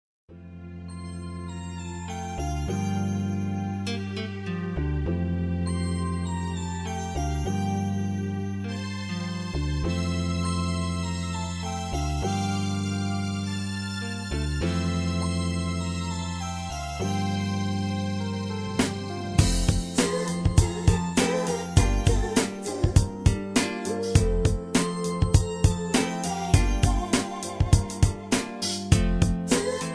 karaoke , sound tracks , backing tracks